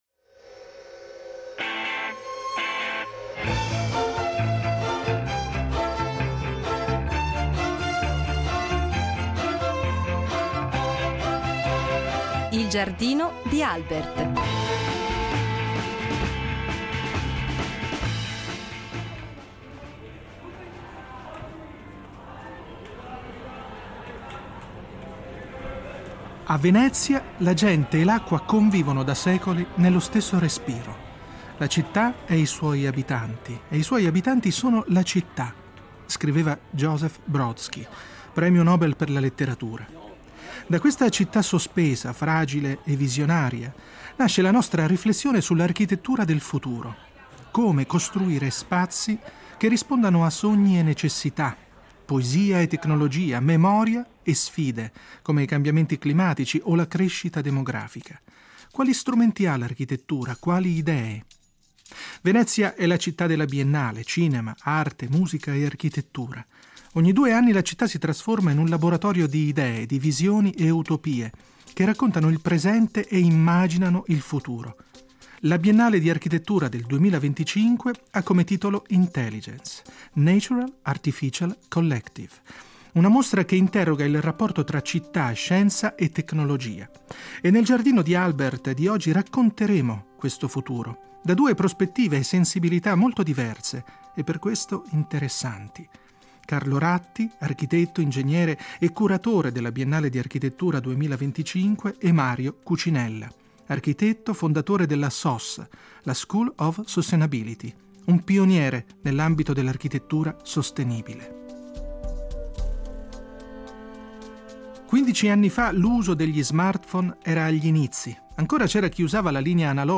Nel Giardino di Albert si confrontano due voci autorevoli dell’architettura contemporanea: Carlo Ratti , architetto, ingegnere e curatore della Biennale, racconta la città come un organismo vivente: non solo pietre e infrastrutture, ma flussi, reti, dati che ne svelano la vita quotidiana; un approccio data-driven che intreccia scienza, tecnologia e progettazione, immaginando spazi capaci di adattarsi e di “cucire un abito” sulla vita metropolitana.